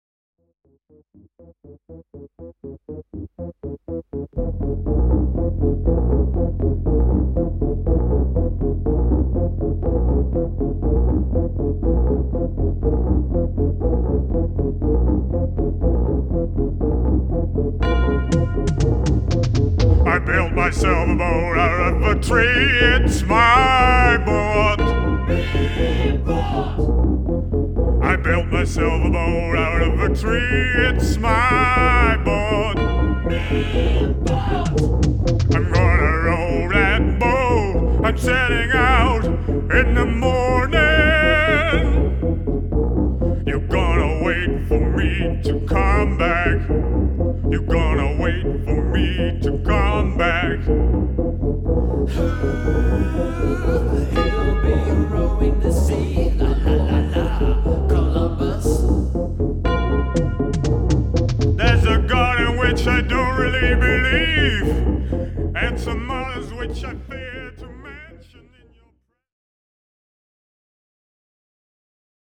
is a more loosely composed and/or improvised work of sound.